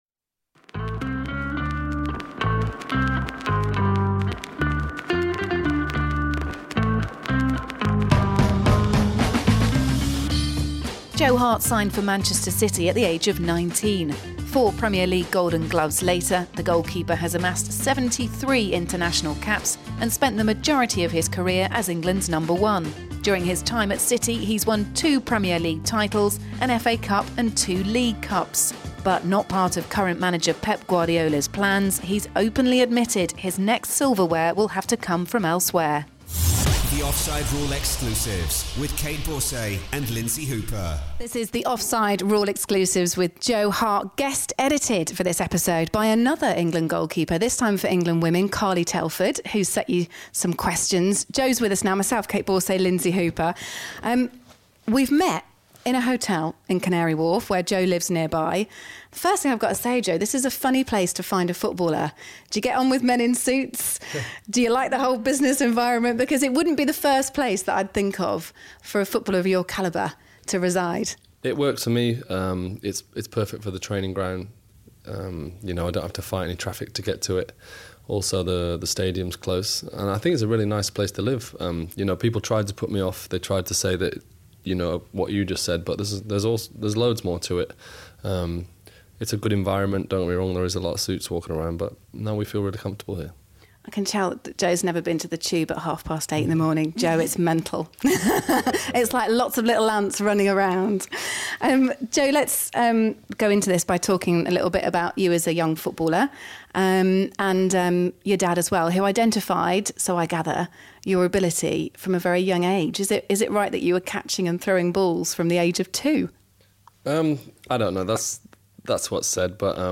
England and West Ham goalkeeper Joe Hart is the guest star on episode two of brand new podcast series The Offside Rule Exclusives.